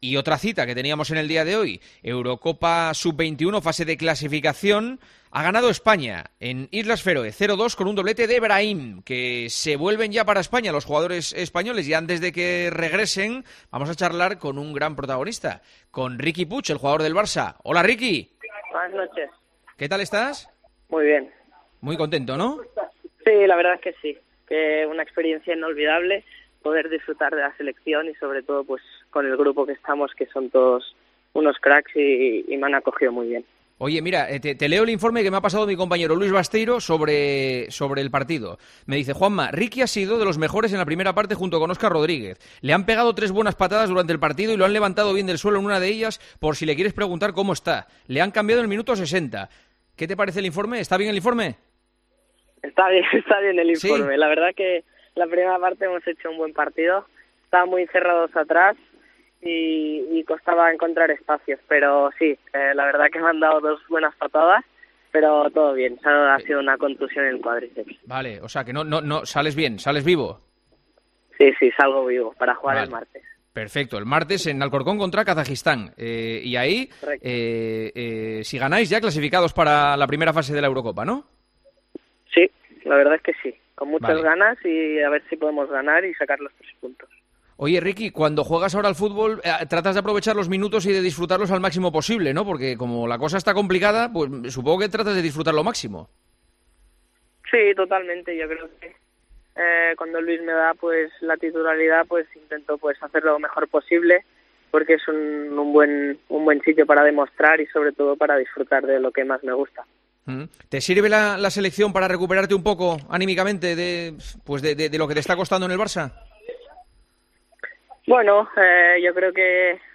El centrocampista sigue muy ilusionado con cada partido que juega con la camiseta de la Selección: "Es una experiencia inolvidable, poder disfrutar de la Selección, con un grupo donde todos son unos cracks y me han acogido muy bien. La Selección es buen sitio para hacer lo que más que gusta", dijo en la conversación que mantuvo con Juanma Castaño.